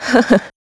Ripine-Vox_Happy1.wav